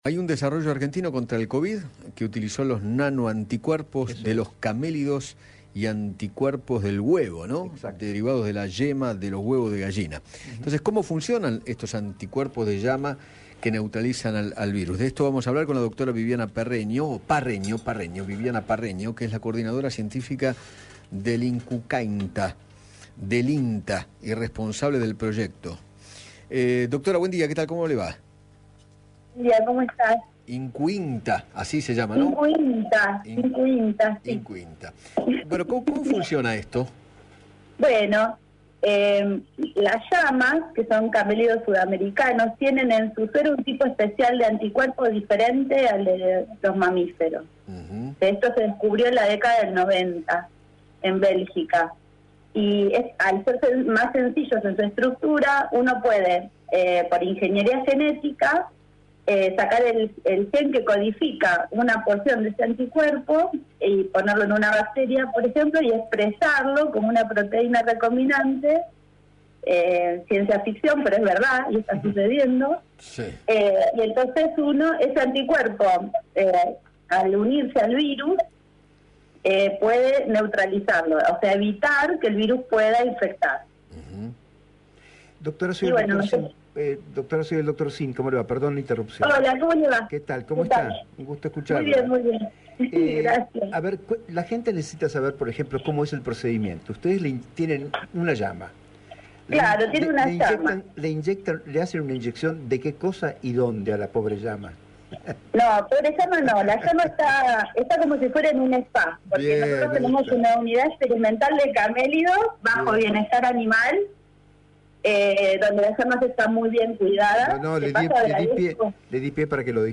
dialogó con Eduardo Feinmann sobre el descubrimiento del Instituto y del CONICET que contrarresta el Covid-19 con anticuerpos derivados de llamas y huevos de gallina, y explicó cómo funciona.